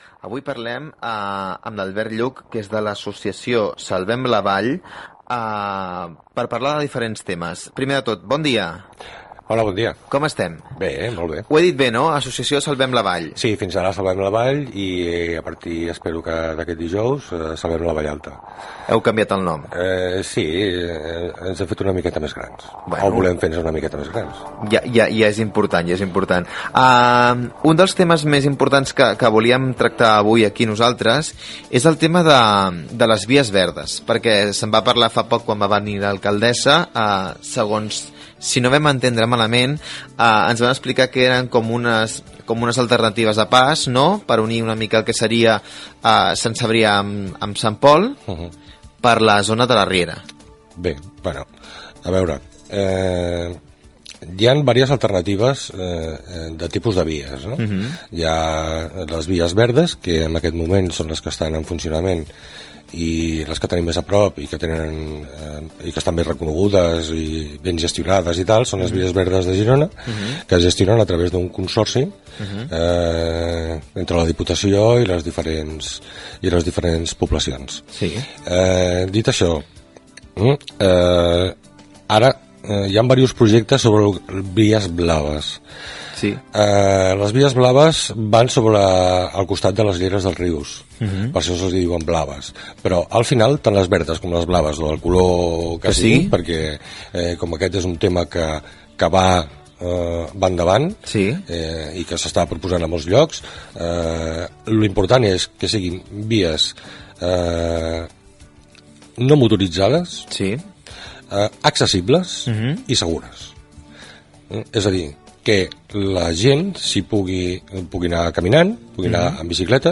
Entrevista
FM